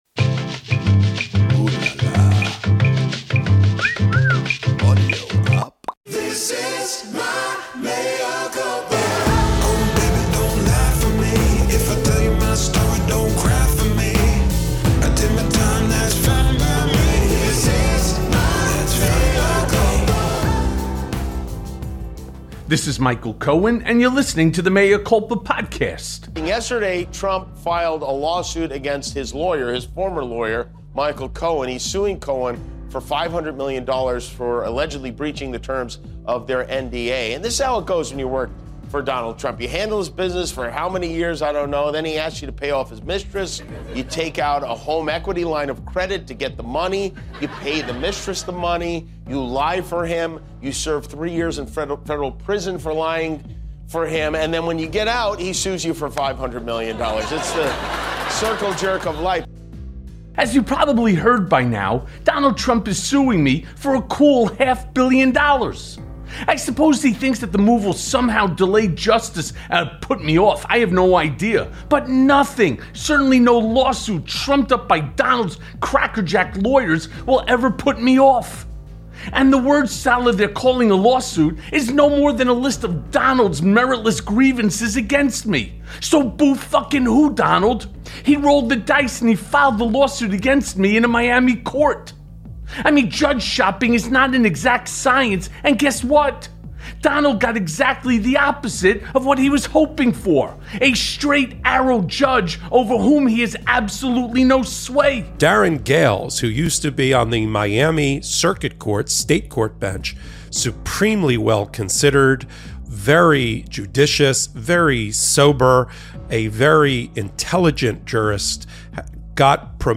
Consequences Loom Large For Fox News + A Conversation with Rick Wilson